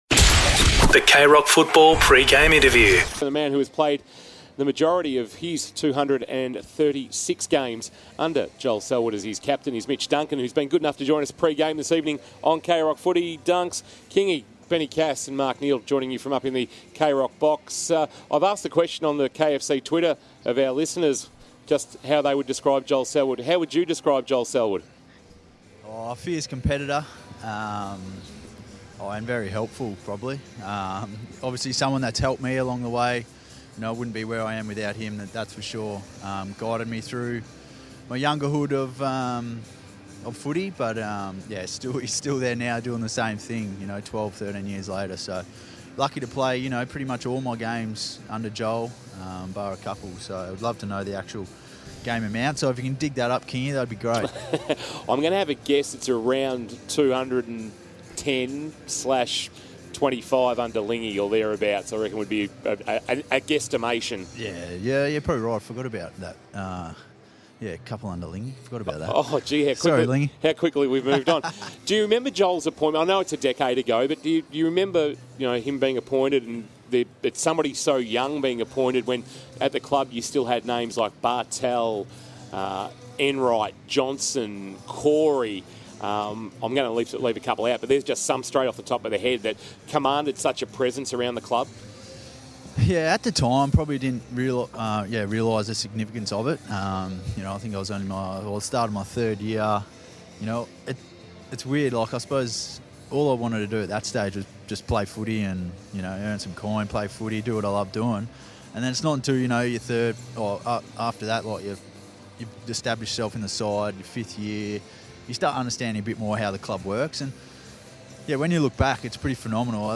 2022 - AFL ROUND 3 - COLLINGWOOD vs. GEELONG: Pre-match Interview - Mitch Duncan (Geelong)